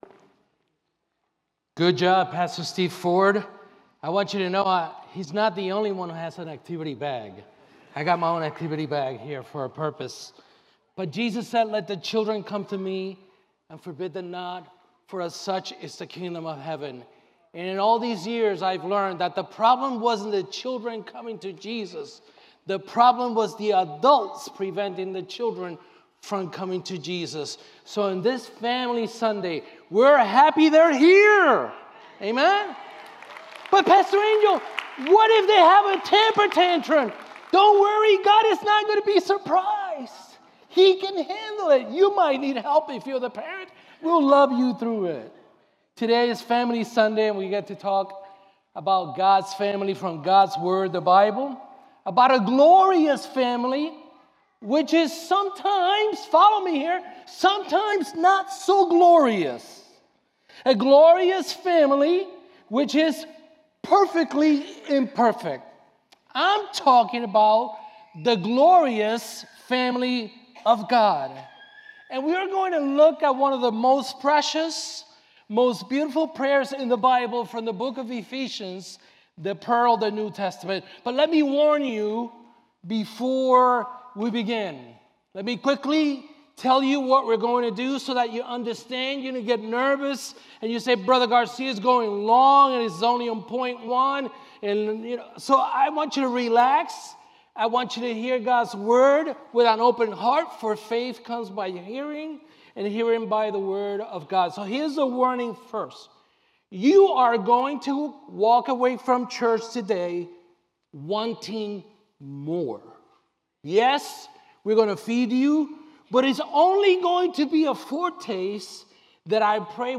Sermons Archive - Mayfair Bible Church